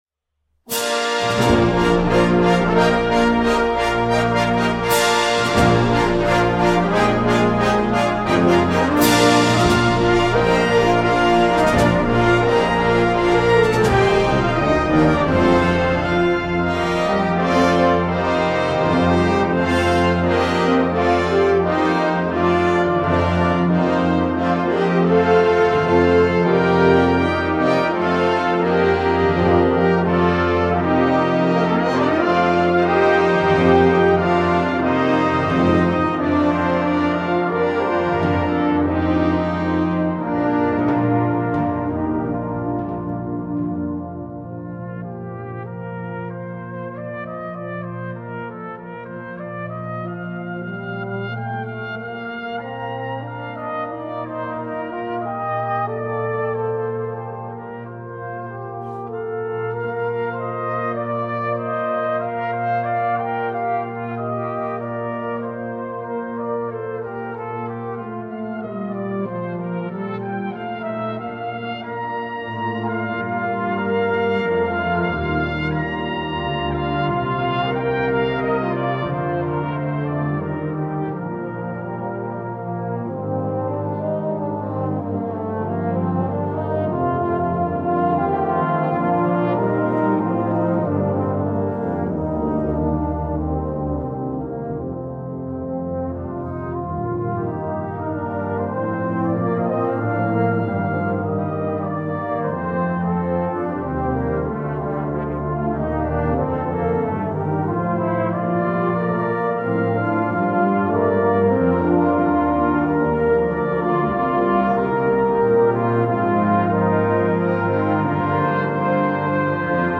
Besetzung: Brass Band & optional Organ